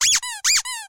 Писк игрушек и не только